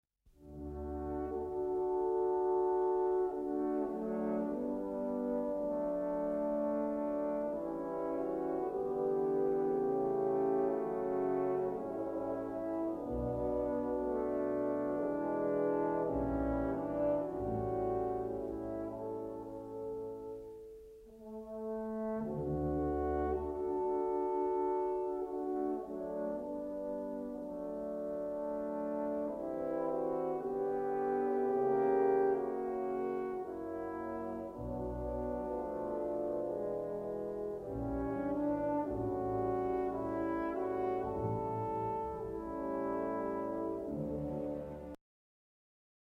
Il moderno corno ha tre pistoni, un canneggio circolare di ottone che termina da un lato con un'ambia svasatura a campana e dall'altro con un bocchino ad imbuto che dà al corno il suo caratteristico timbro soffice e vellutato.
quartetto di corni in orchestra
corni_quartetto.mp3